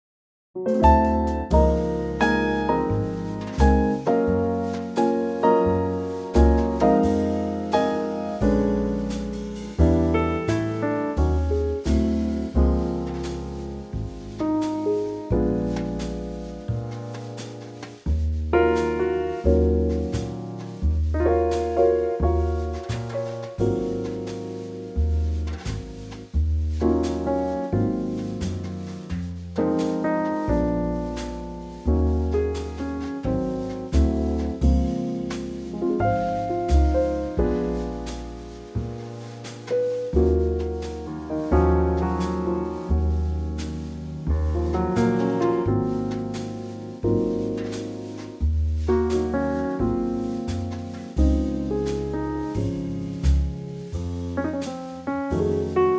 Unique Backing Tracks
key - Db - vocal range - Bb to C
Here's a gorgeous Trio arrangement.